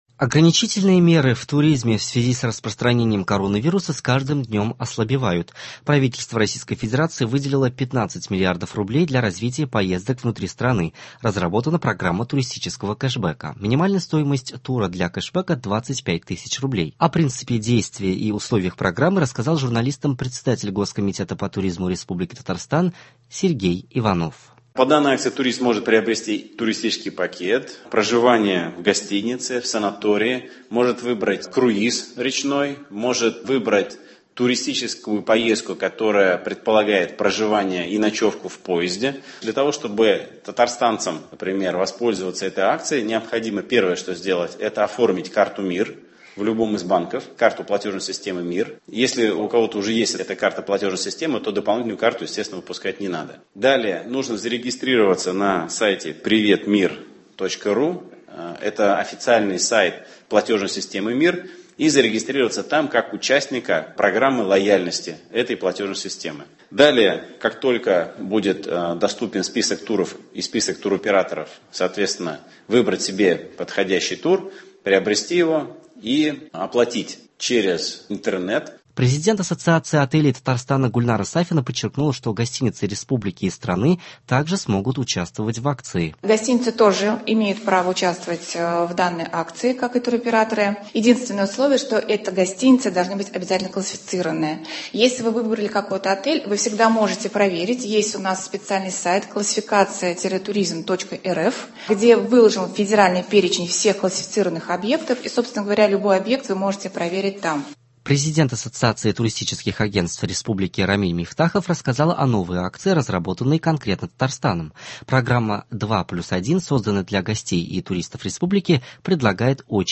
Новости. 3 августа.